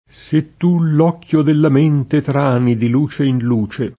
trainare v.; traino [tr#ino; antiq. tra&no] — dell’uso ant., nel sign. (non tecnico) di «trascinare», la var. tranare: trano [tr#no], con riduzione del dittongo discendente: se tu l’occhio de la mente trani Di luce in luce [